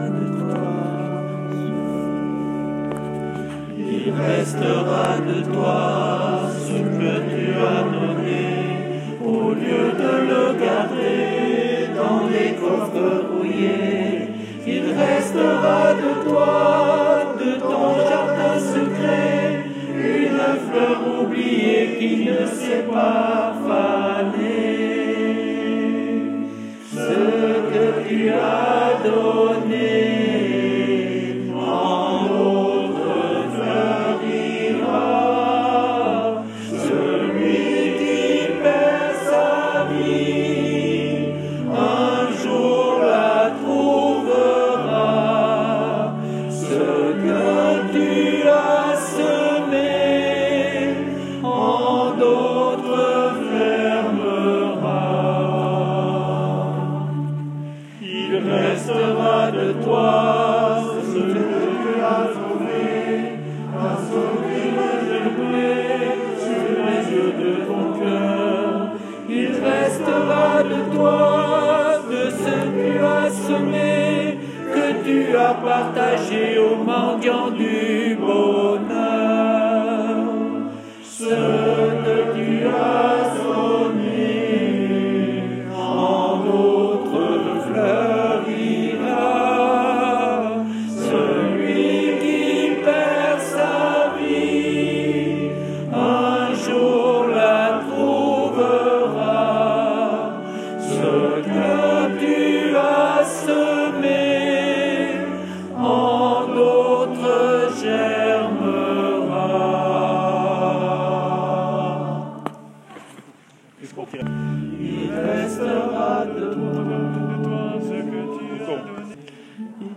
chant liturgique